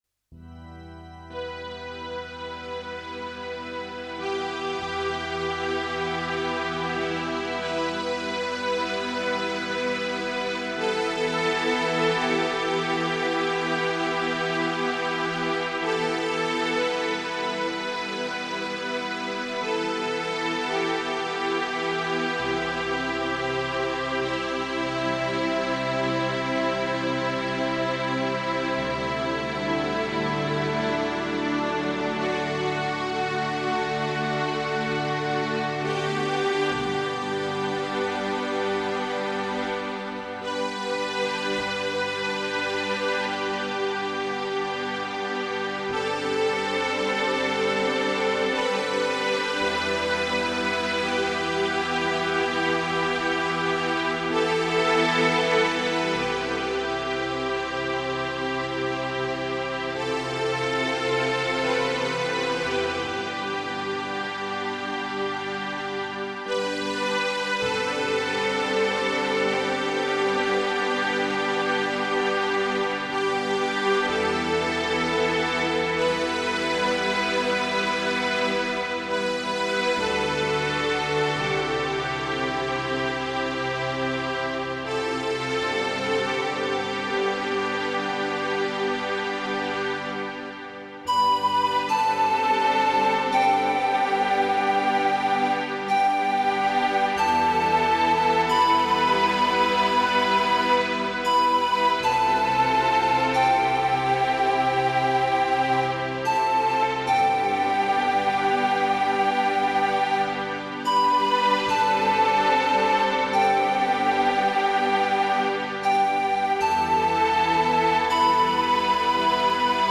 Musique new age: